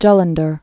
(jŭlən-dər)